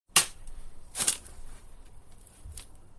shovel3.ogg